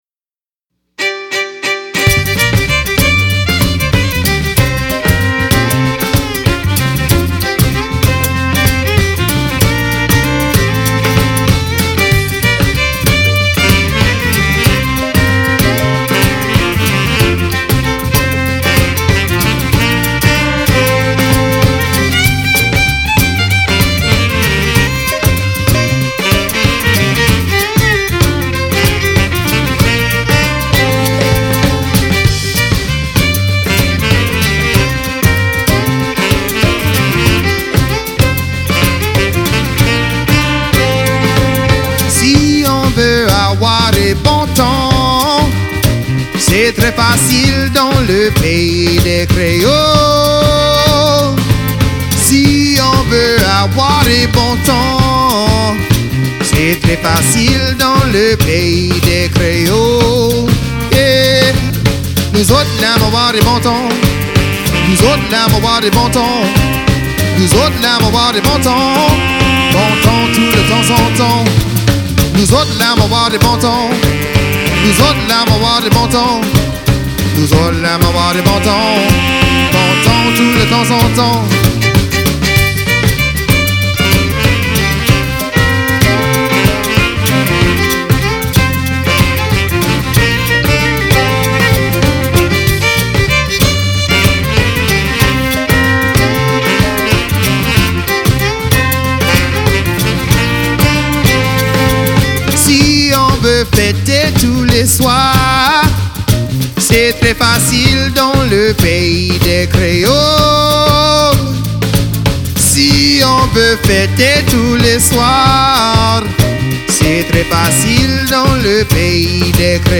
accordéon, violon, voix
frottoir, trompette
basse
batterie
guitare
chants cajun, zydeco, bluegrass